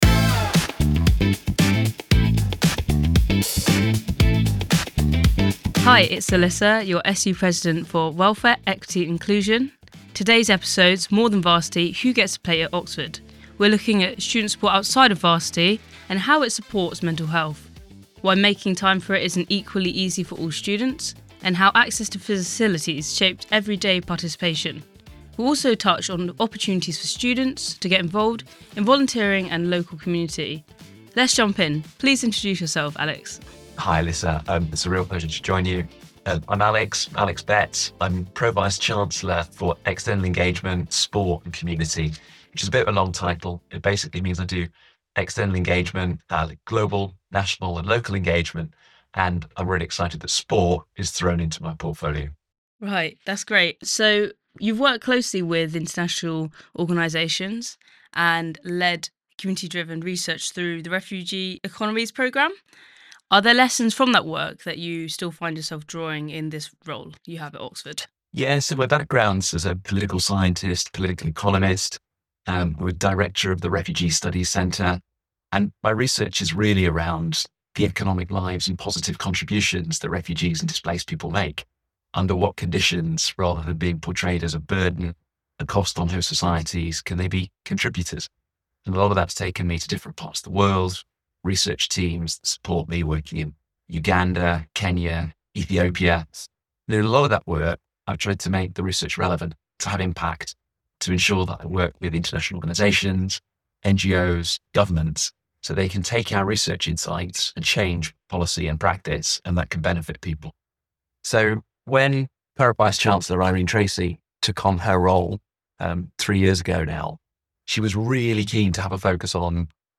The conversation focuses on sport, wellbeing and belonging.